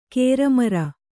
♪ kēra mara